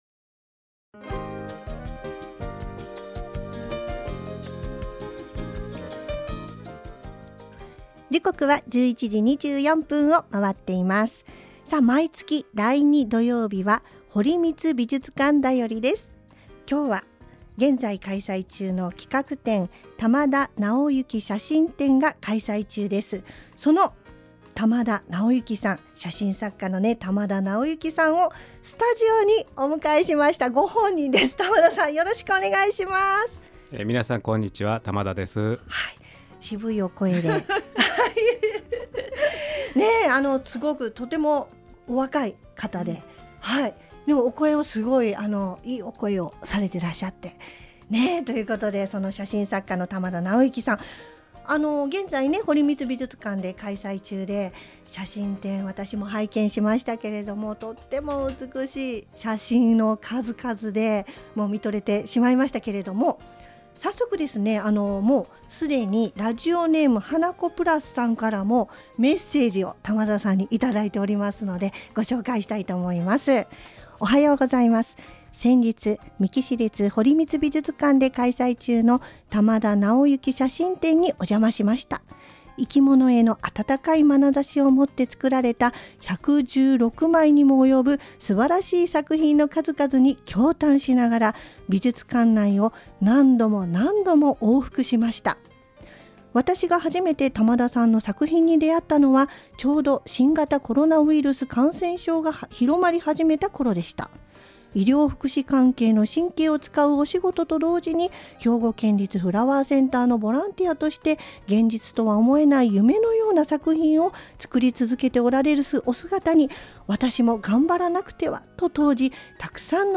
エフエムみっきぃ のようす